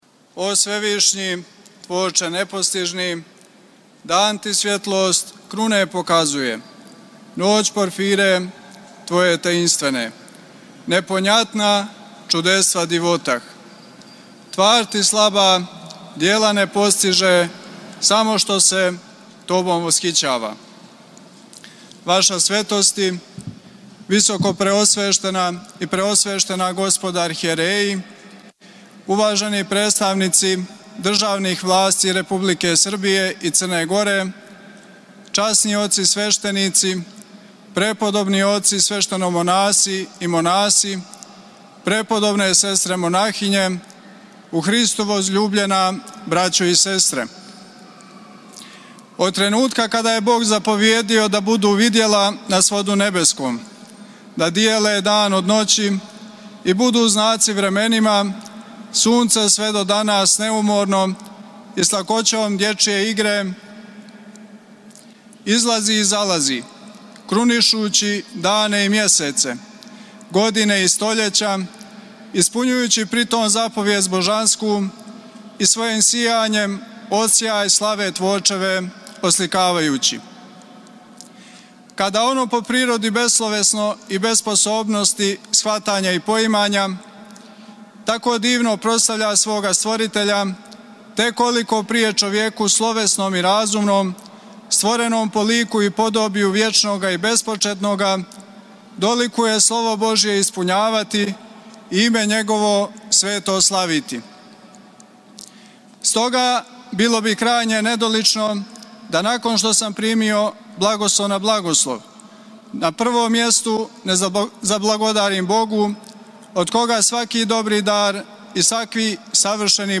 Његова Светост Патријарх српски г. Порфирије је началствовао 22. септембра 2024. године у храму Светог Саве у Београду светом архијерејском Литургијом и свечаним чином хиротоније изабраног и нареченог високодостојног архимандрита Пајсија за Епископа диоклијског, викара Митрополита црногорско-приморског.
Послушајте у целости звучни запис приступне беседе Његовог Преосвештенства Епископа диоклијског г. Пајсија (Ђерковића), викара Митрополита црногорско-приморског:
Beseda Episkopa Pajsija Hirotonija 22.09.mp3